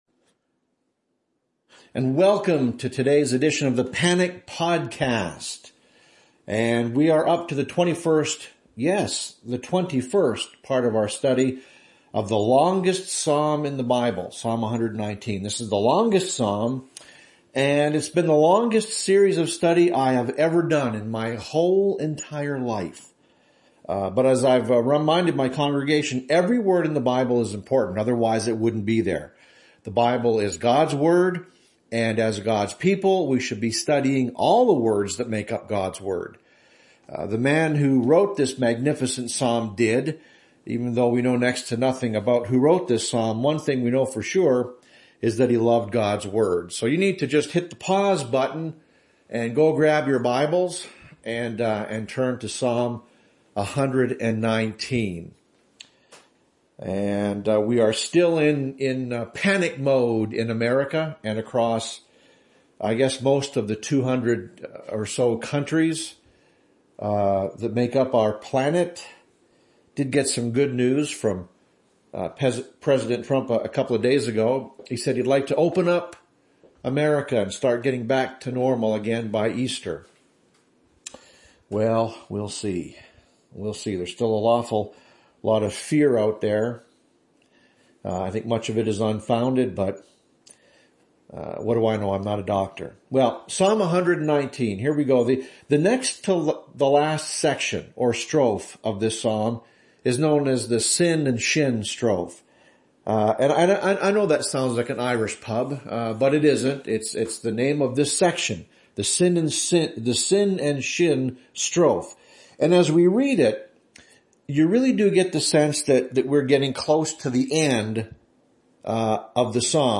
Here’s the audio portion of our Bible study tonight. It’s part 21 of an in-depth study of Psalm 119.